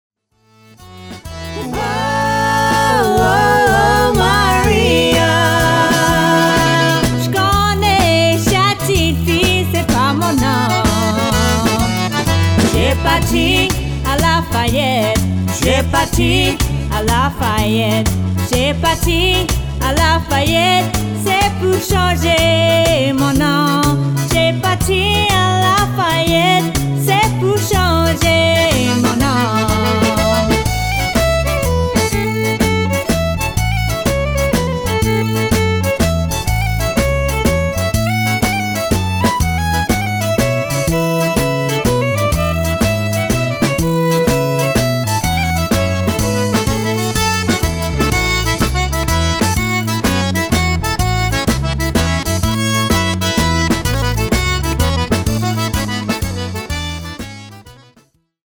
accordions and vocals and acoustic guitar
bass
petite fer